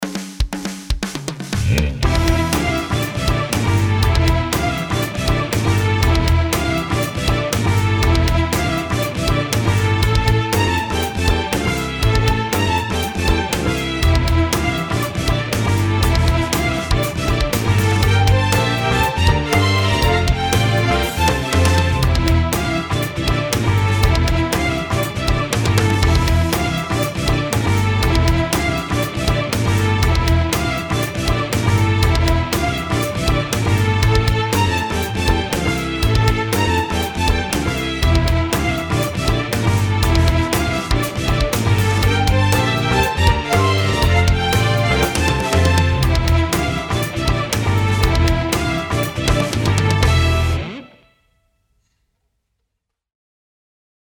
音楽ジャンル： メタル
楽曲の曲調： HARD